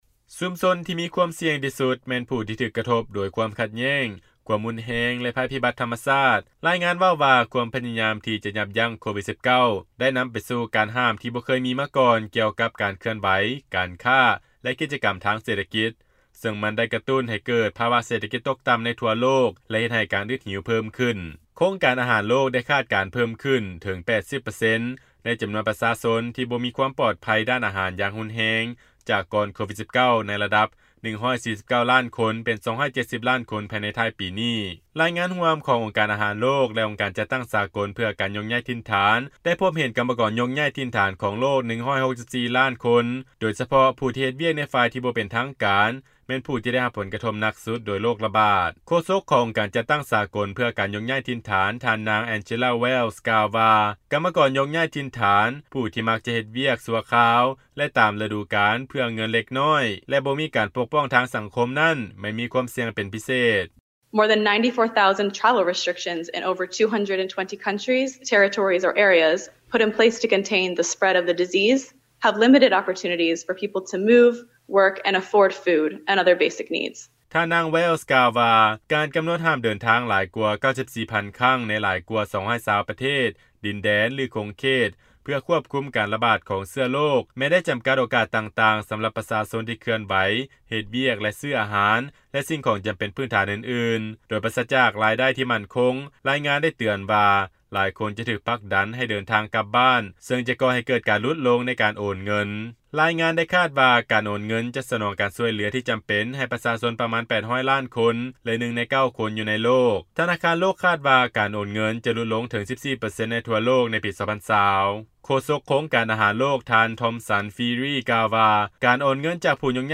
ຟັງລາຍງານ ມີຄວາມອຶດຫິວ ແລະ ການຍົກຍ້າຍຖິ່ນຖານເພີ່ມຂຶ້ນ ໃນຂະນະທີ່ໂຣກລະບາດ ສົ່ງຜົນກະທົບຢ່າງໜັກ ຕໍ່ເສດຖະກິດ